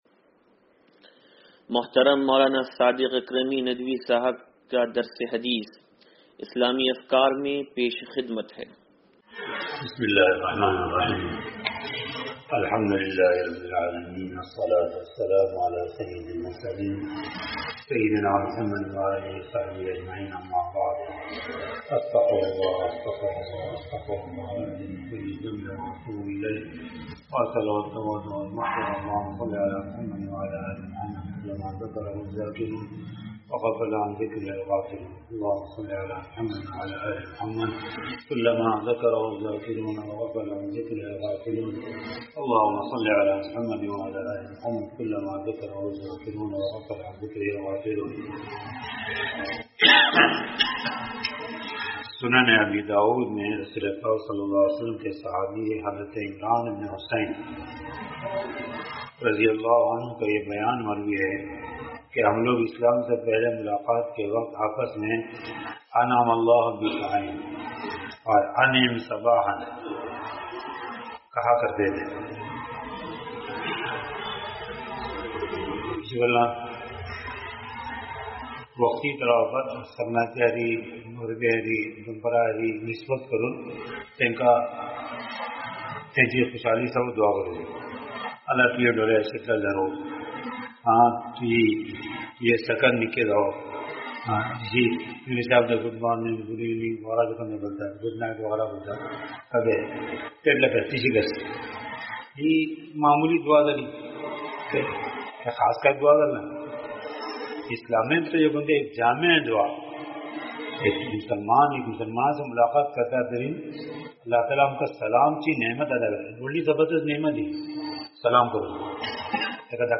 درس حدیث نمبر 0441